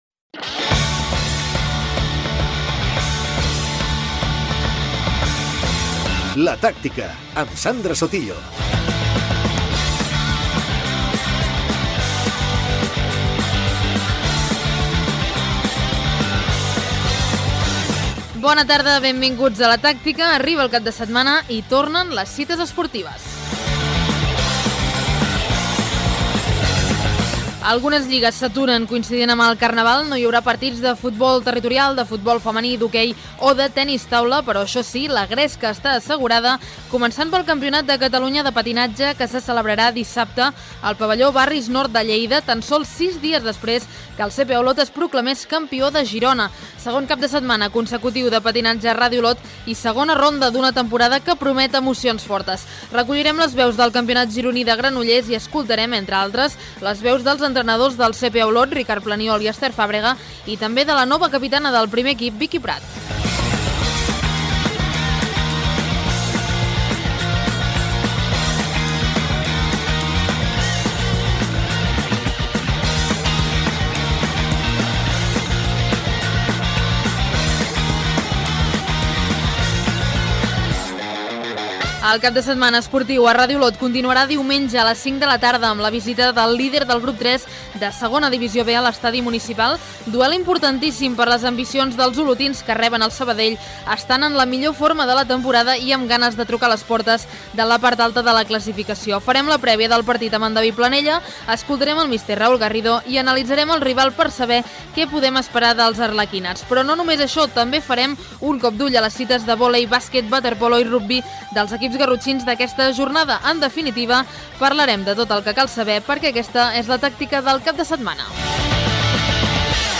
Careta del programa, calendari d'activitats esportives del cap de setmana i transmissions de Ràdio Olot
Esportiu